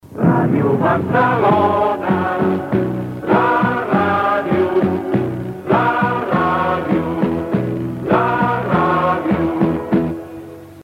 Indicatiu cantat de l'emissora a ritme de sardana.